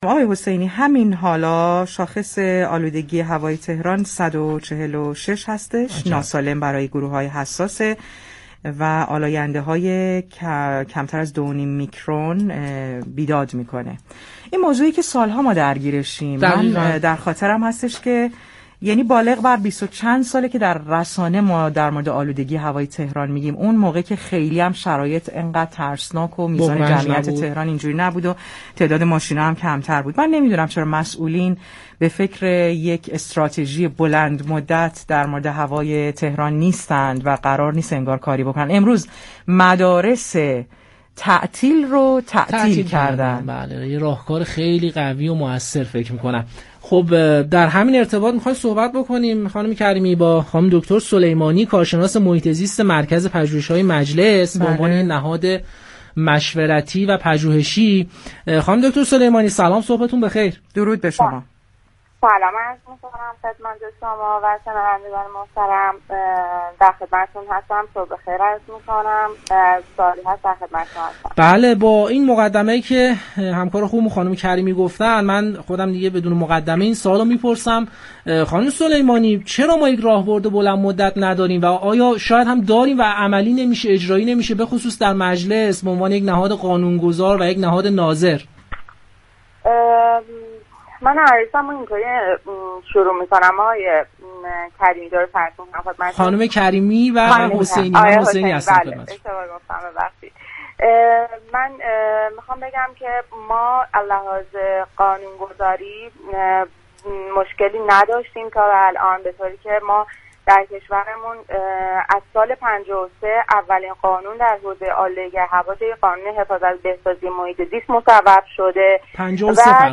در گفتگو با پارك شهر رادیو تهران